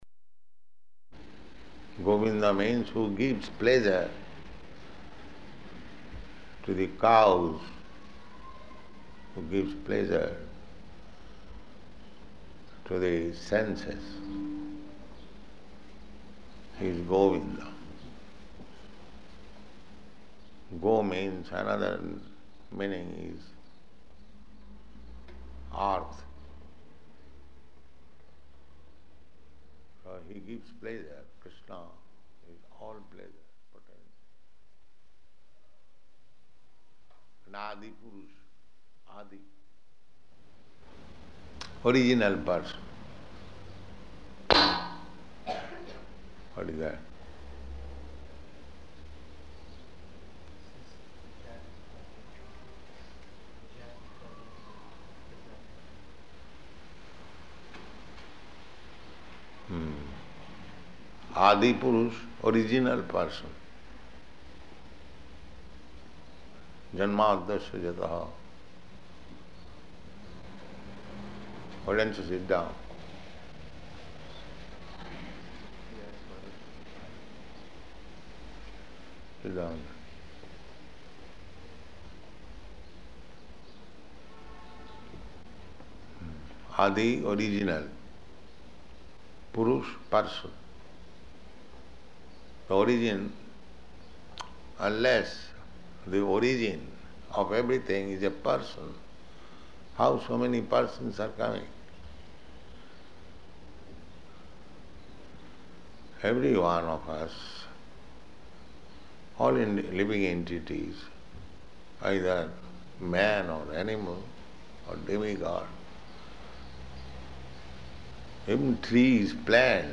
Śrī Brahma-saṁhitā Lecture
Location: New York
[crashing sound] What is that?